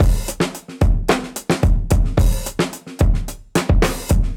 Index of /musicradar/dusty-funk-samples/Beats/110bpm
DF_BeatB_110-04.wav